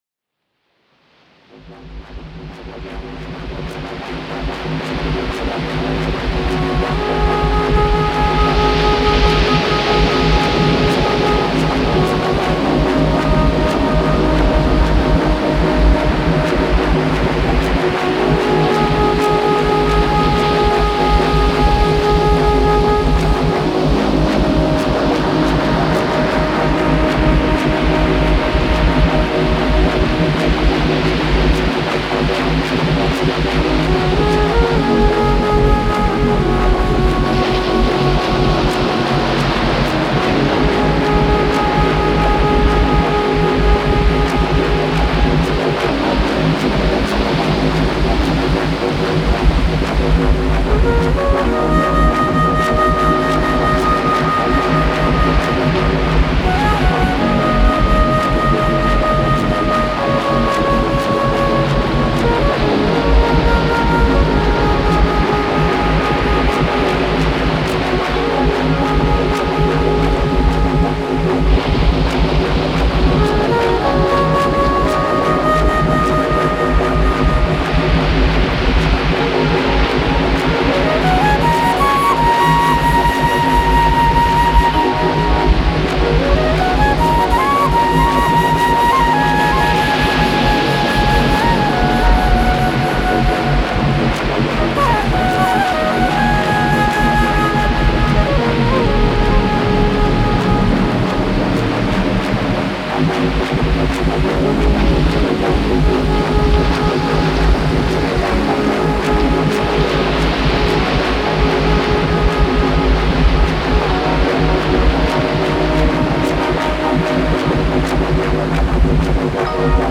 flute improvisations